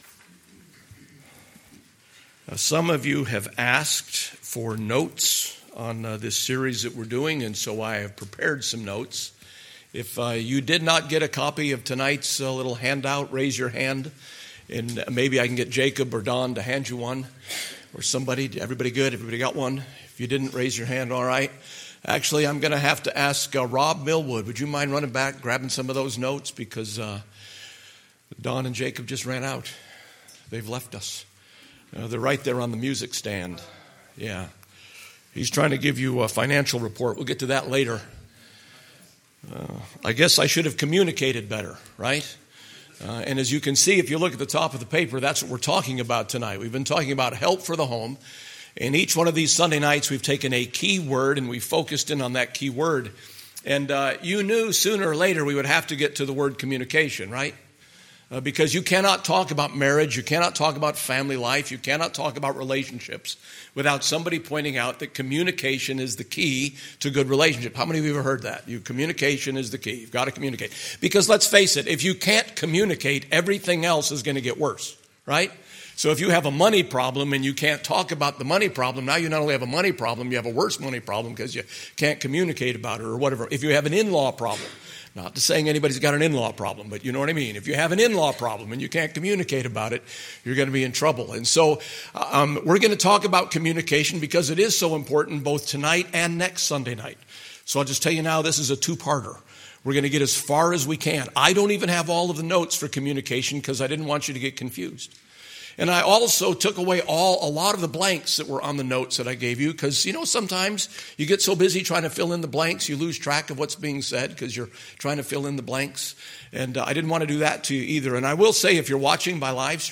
Communication begins before we ever say a word, and in tonight’s practical message we’ll consider some important principles as well as recognize the barriers to effective communication.